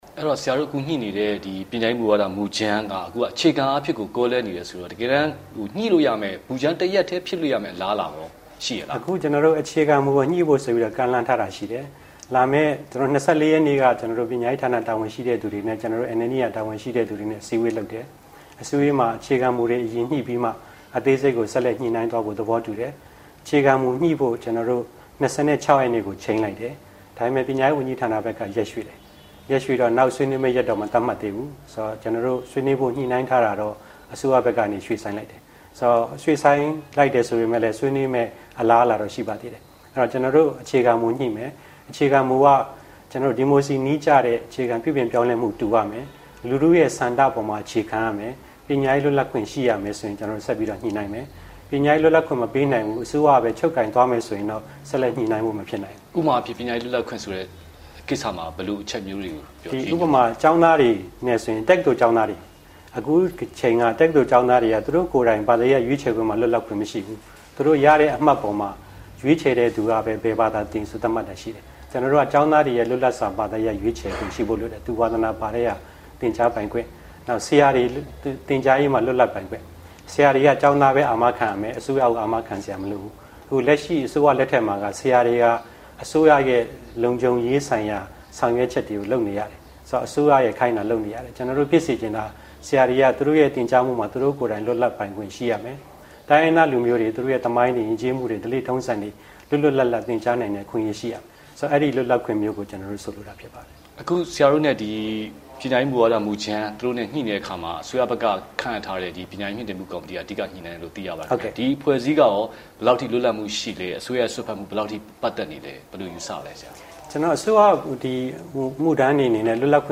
ဗွီအိုအေ မေးမြန်းခန်း